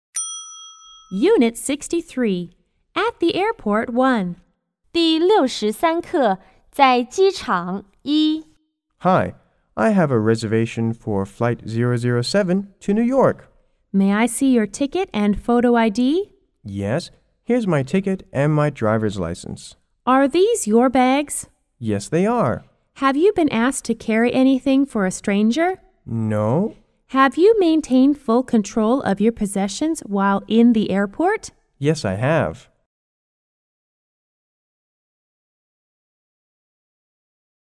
T= Traveler R= Receptionist